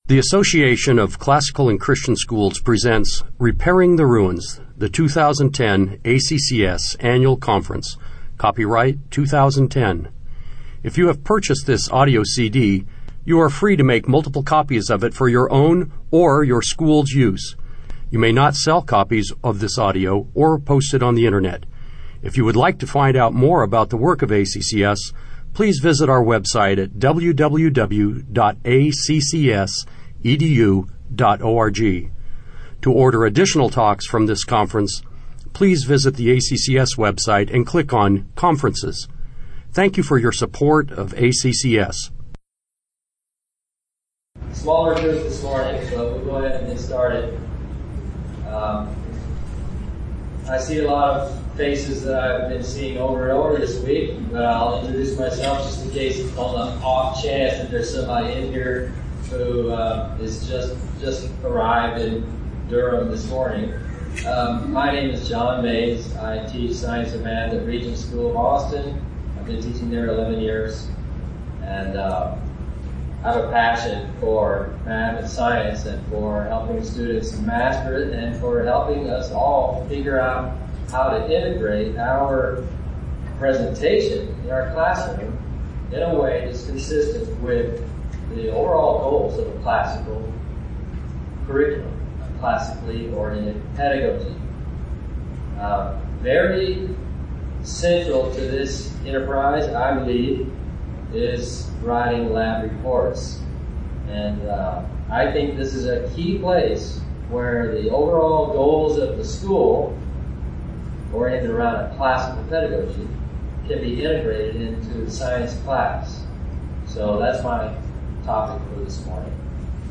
2010 Workshop Talk | 1:03:36 | 7-12, Science